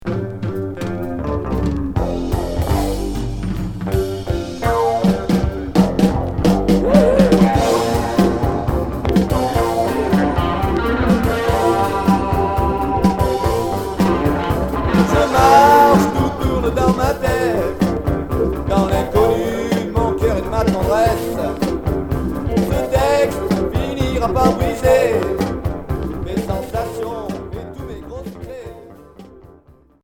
Pop rock Premier 45t retour à l'accueil